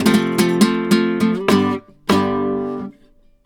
Index of /90_sSampleCDs/USB Soundscan vol.59 - Spanish And Gypsy Traditions [AKAI] 1CD/Partition B/04-70C RUMBA